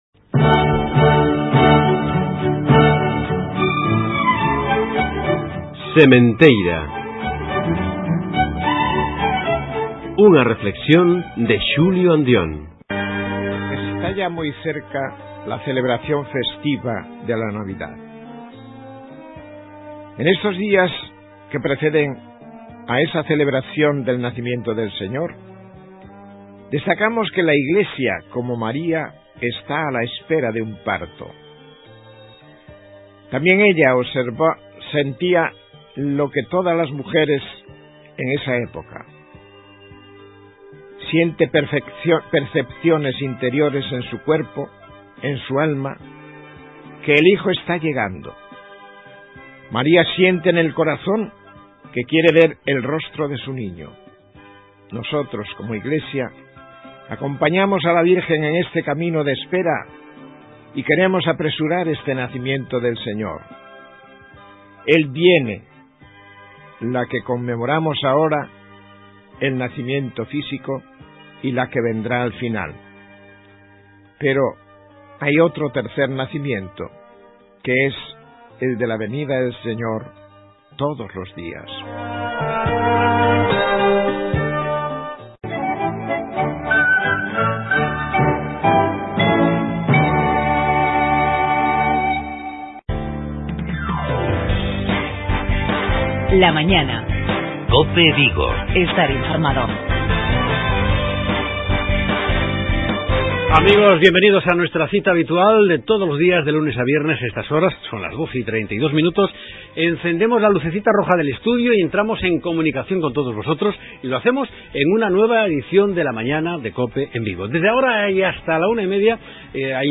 Arrancaremos el magazine con una tertulia postelectoral